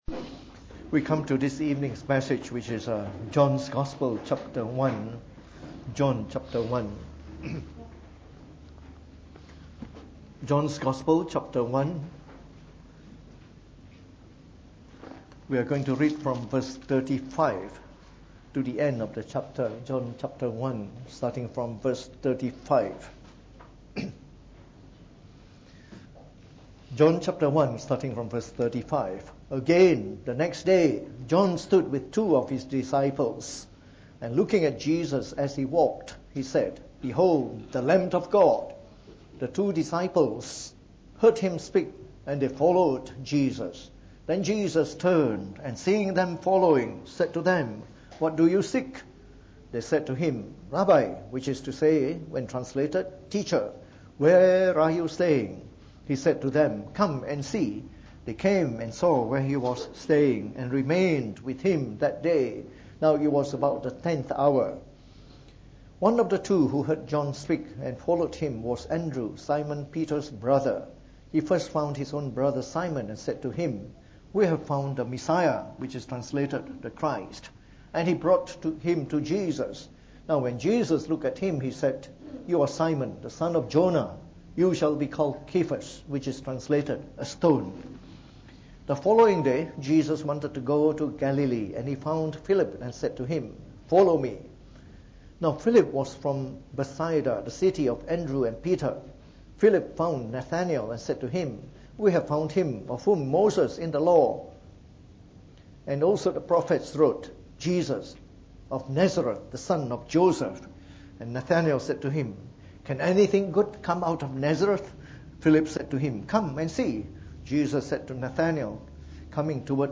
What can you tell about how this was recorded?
From our series on the Gospel of John delivered in the Evening Service.